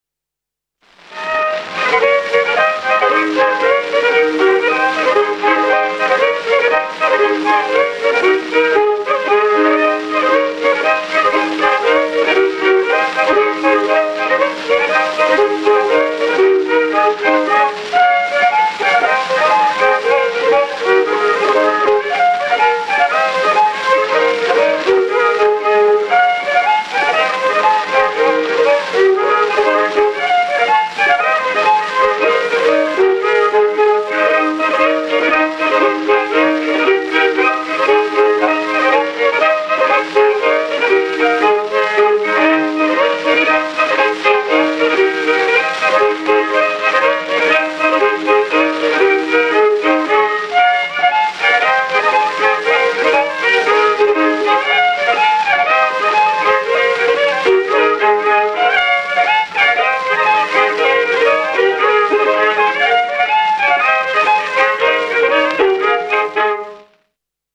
I viiul
II viiul
08 Polka.mp3